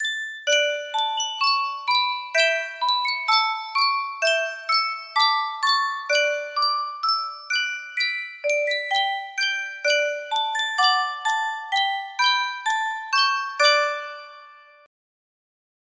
Superstar Music Box - America the Beautiful O9 music box melody
Full range 60